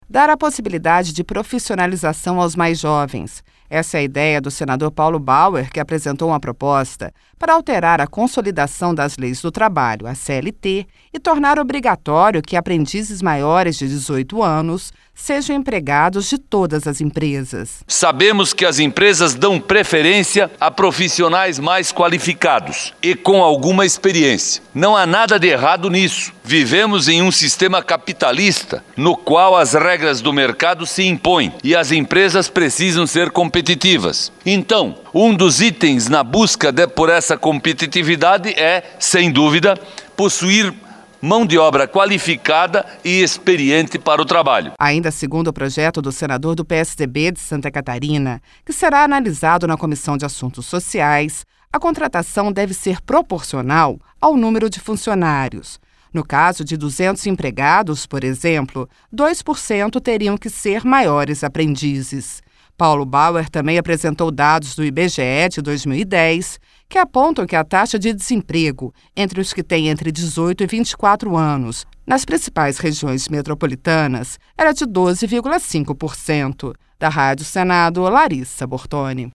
(REPÓRTER) Dar a possibilidade de profissionalização aos mais jovens.